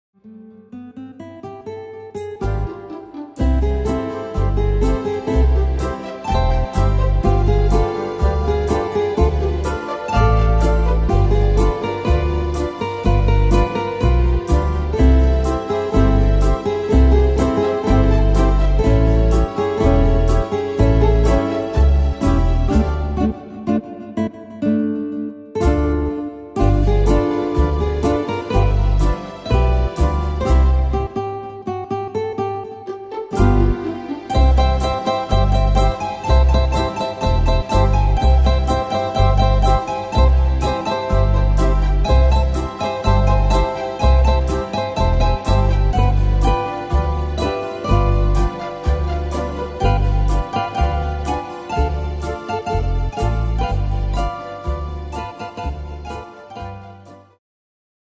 Tempo: 62 / Tonart: C-Dur